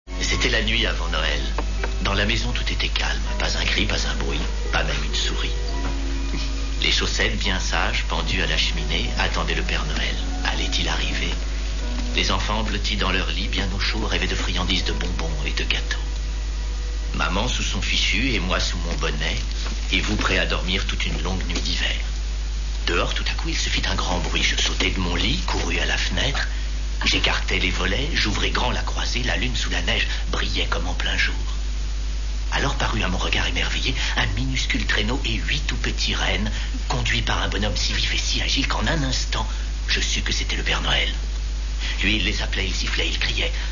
Jarod racontant un conte de Noel (Anglais):(Real audio)/(MP3).